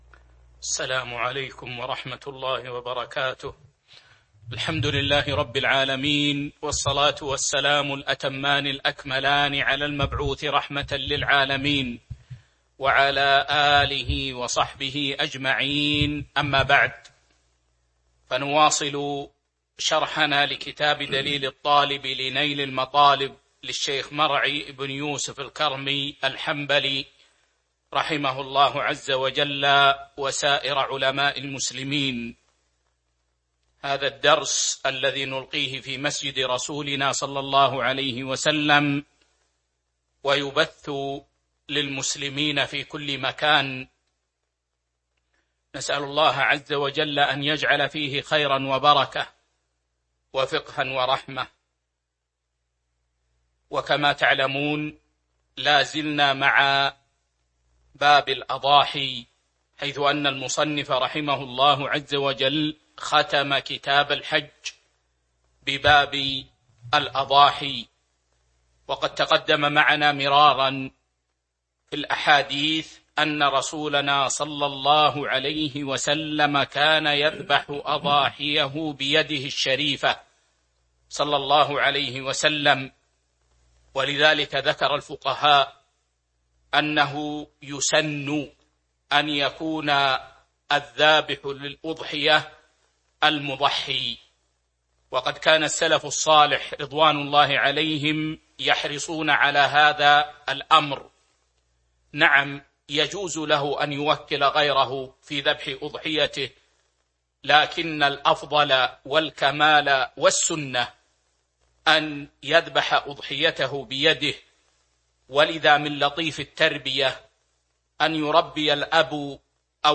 تاريخ النشر ٣ جمادى الآخرة ١٤٤٢ هـ المكان: المسجد النبوي الشيخ